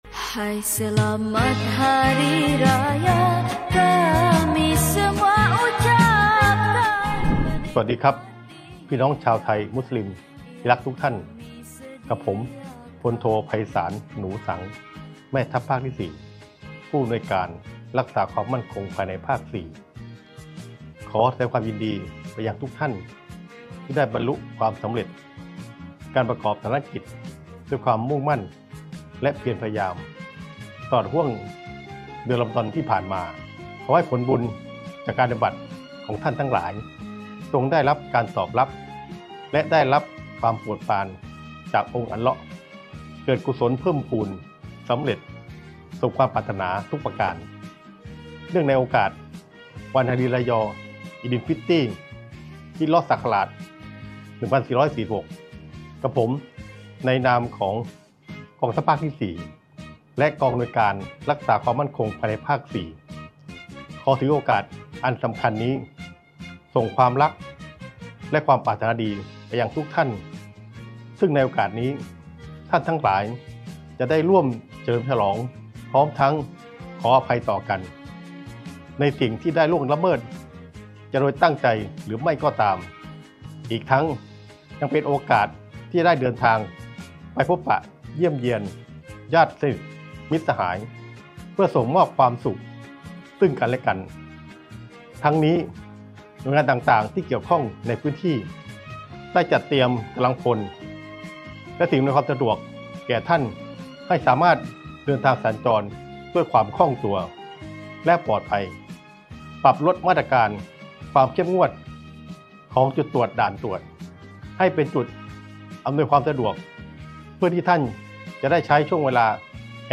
แม่ทัพภาคที่ 4 ส่งคำอวยพรพี่น้องชาวไทยมุสลิม เนื่องในโอกาสวันฮารีรายออีฎิ้ลฟิตริ ฮ.ศ.1446 | กอ.รมน.ภาค 4 สน.
แม่ทัพภาคที่-4-ฮารีรายอ-อีฎิ้ลฟิตริ-ฮิจเราะห์ศักราช-1446-เสียงวิทยุ.mp3